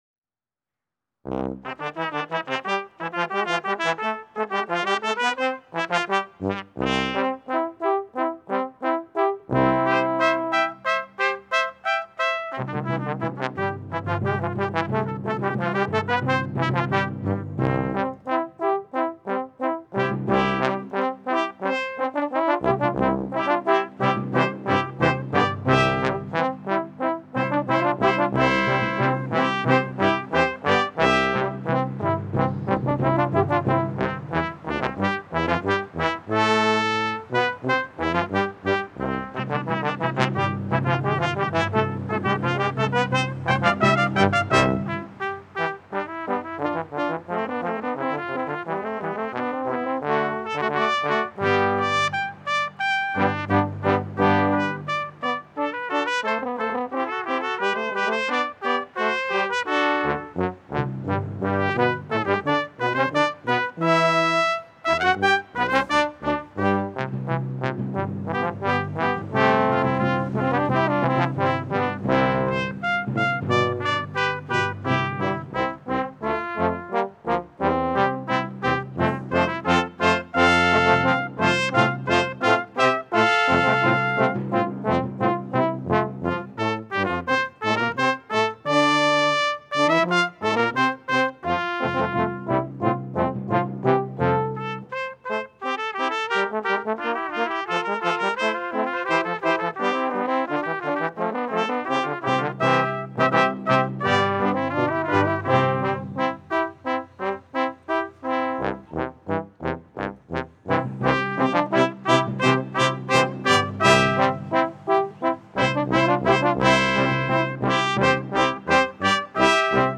Sanctuary-April-11-audio.mp3